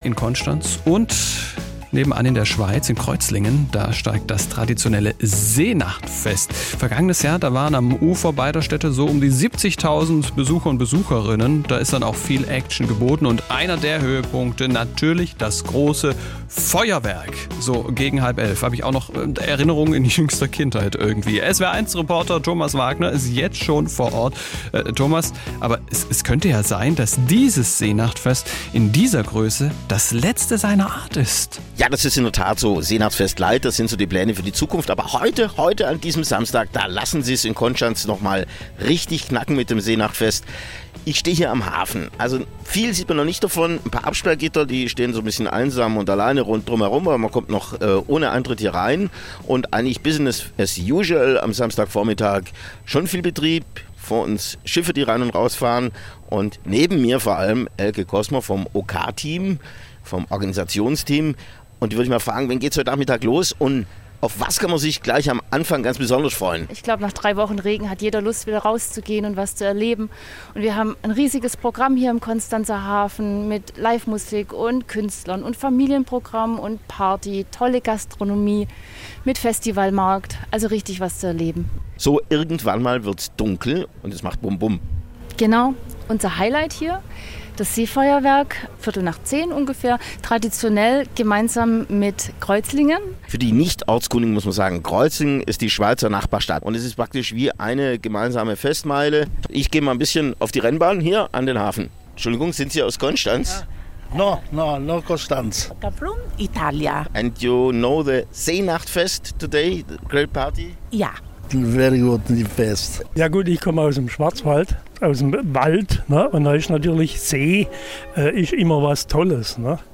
SWR Reporter
war schon seit dem Vormittag in Konstanz und berichtete über die steigende Stimmung: